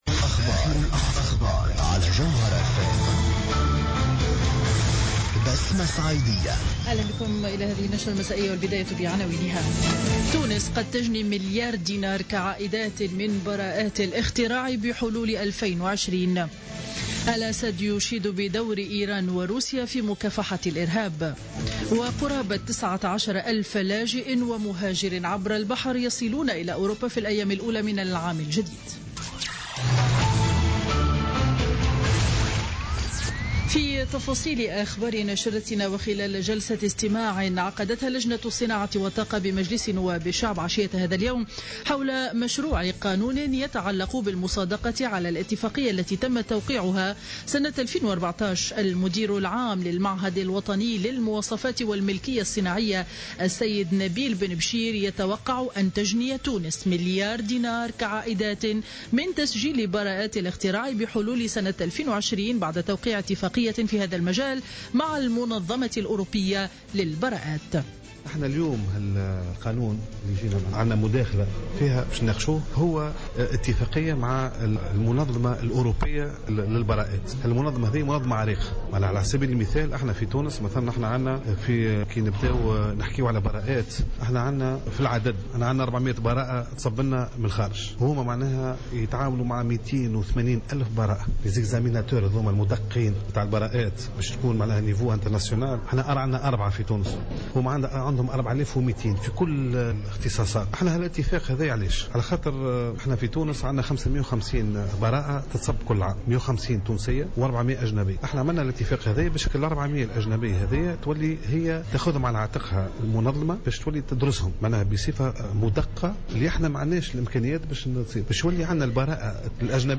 نشرة أخبار السابعة مساء ليوم الثلاثاء 12 جانفي 2016